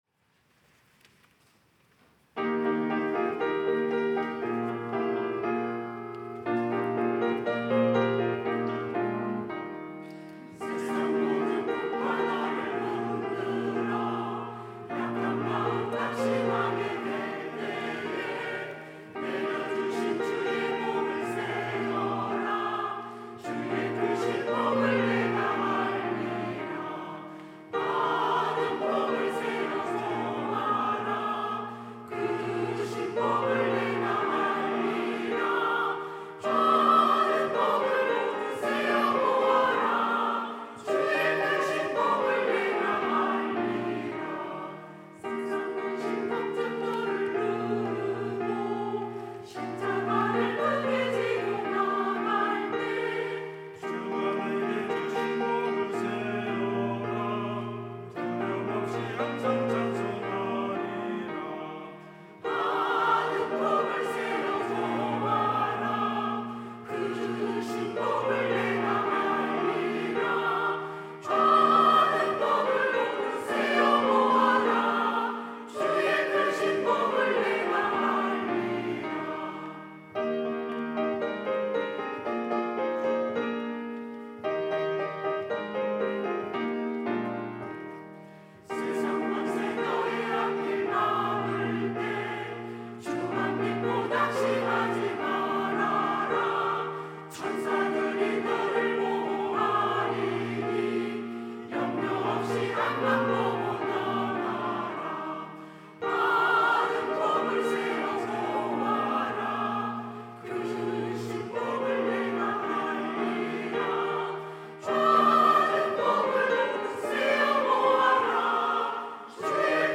천안중앙교회
찬양대 가브리엘